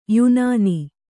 ♪ yunāni